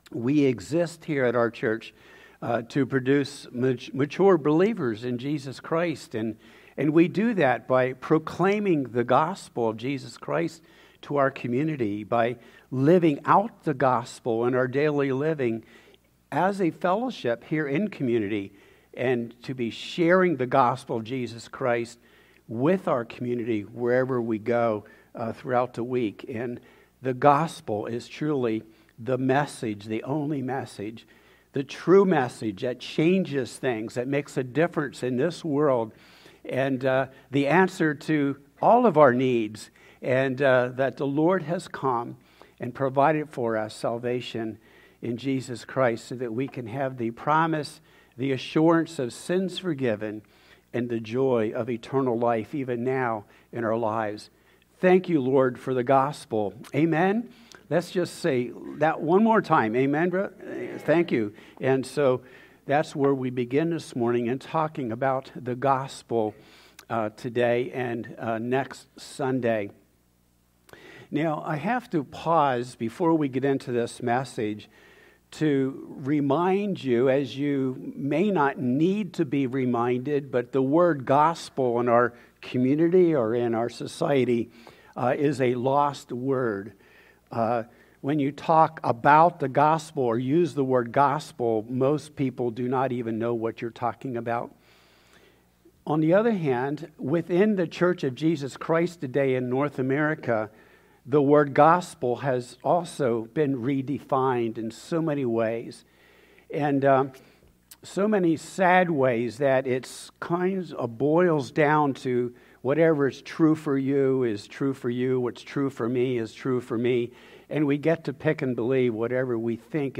3-8-26-Sermon-Living-between-the-times.mp3